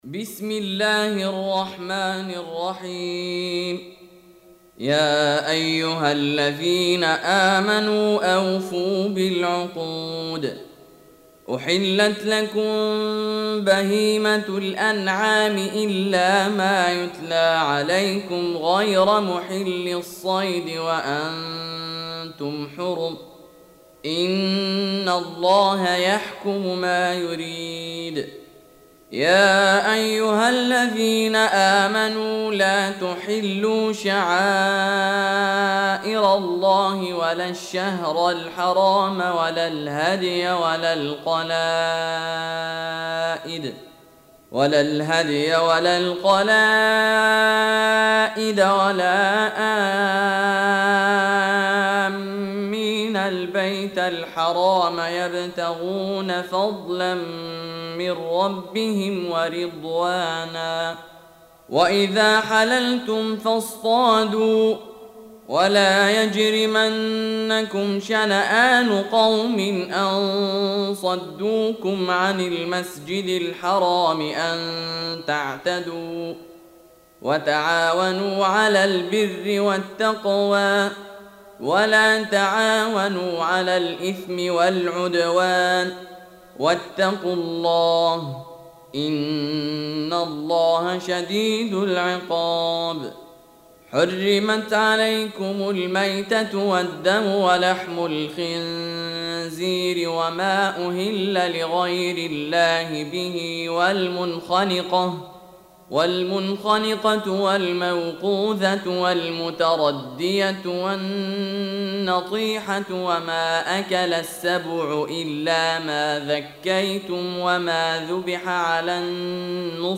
Surah Sequence تتابع السورة Download Surah حمّل السورة Reciting Murattalah Audio for 5. Surah Al-M�'idah سورة المائدة N.B *Surah Includes Al-Basmalah Reciters Sequents تتابع التلاوات Reciters Repeats تكرار التلاوات